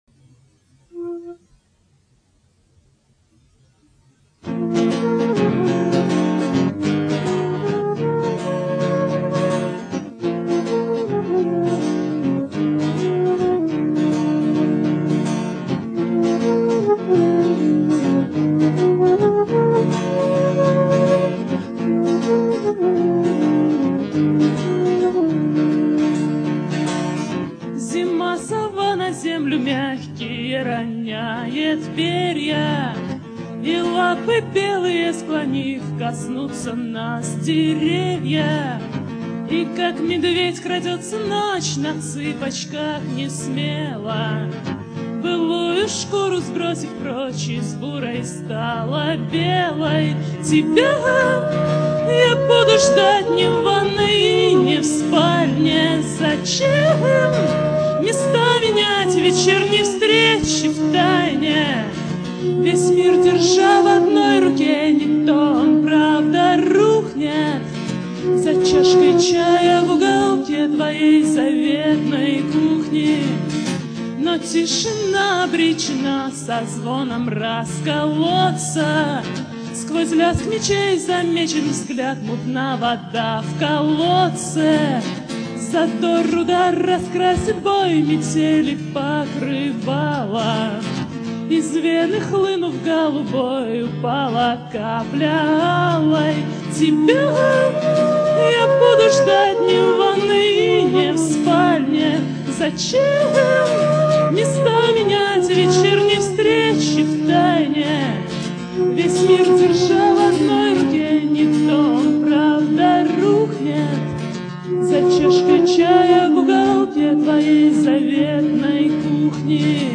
Выступление в театре "Перекресток" 23 января 2001 года.